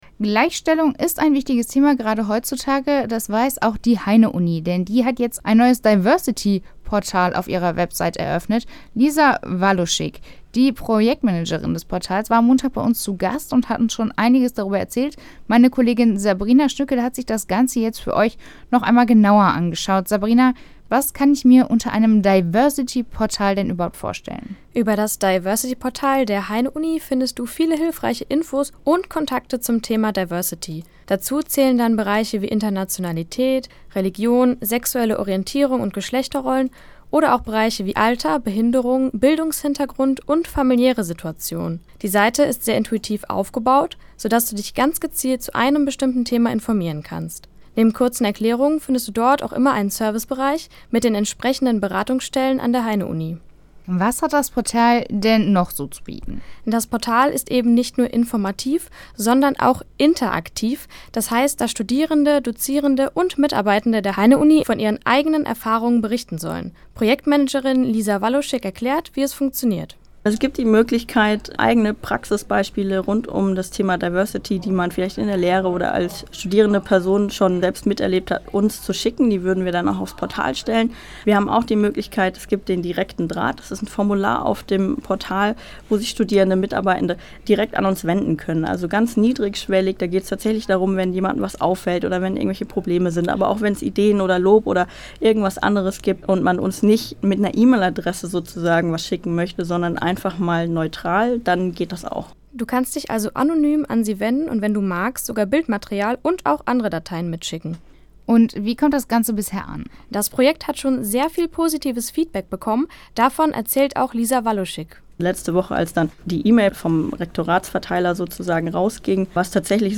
Bericht